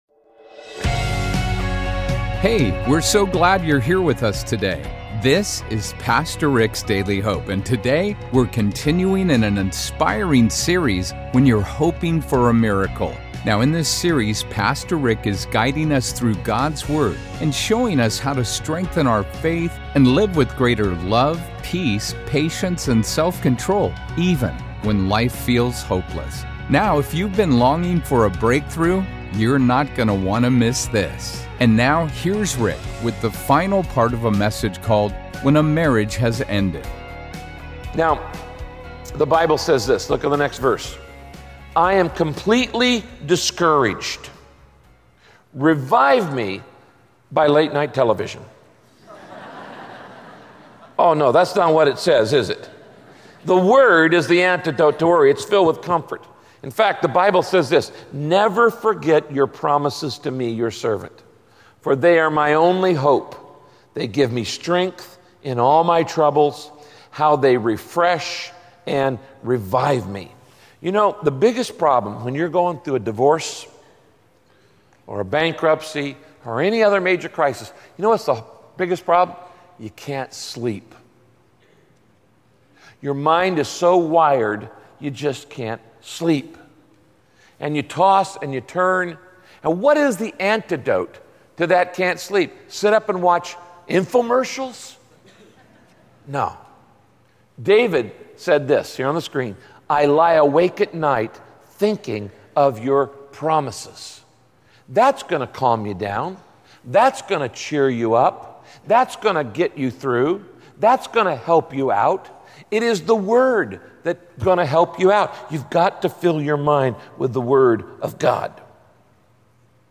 In this message, Pastor Rick teaches how, even during difficult times, you can strengthen a friend's faith by demonstrating your faith in God's goodness.